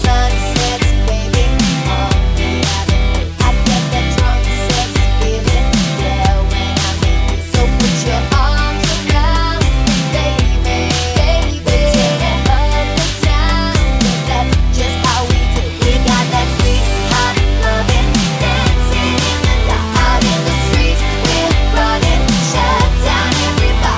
Clean Pop (2010s) 4:03 Buy £1.50